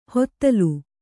♪ hottalu